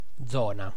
Ääntäminen
IPA : /bɛlt/